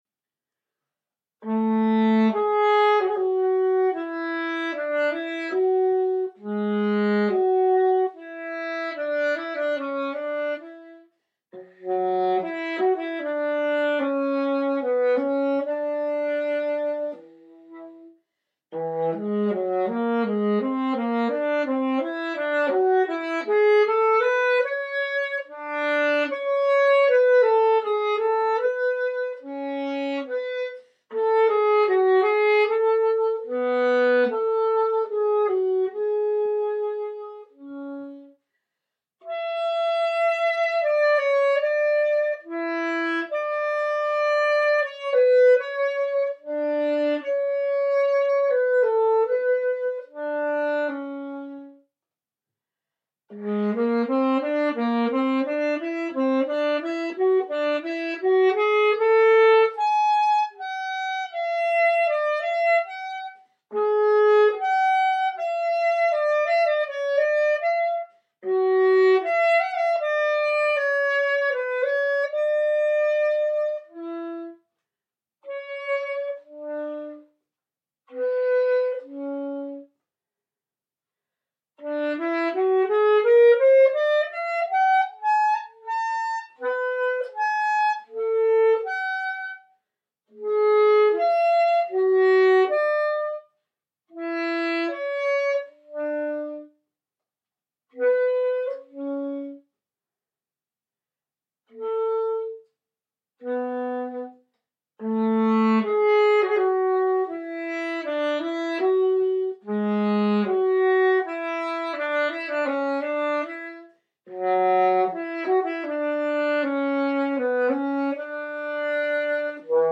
F sharp and B Whole tone